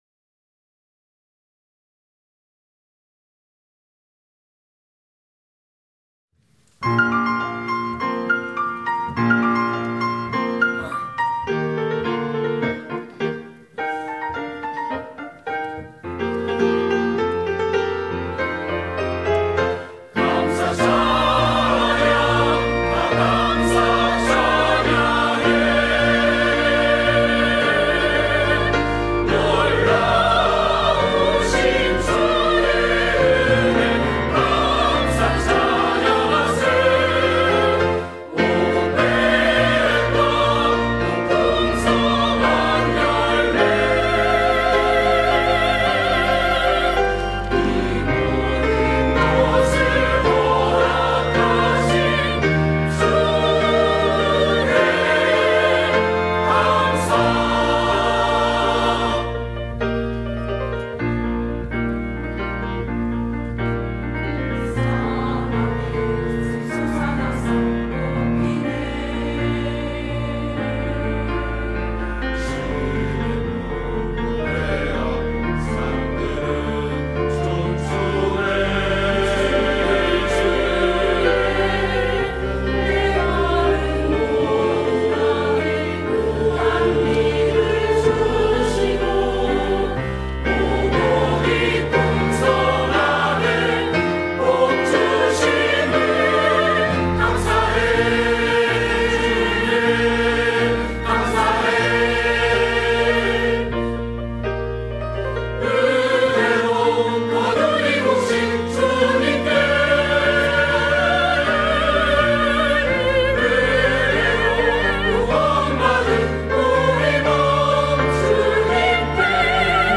감사 찬양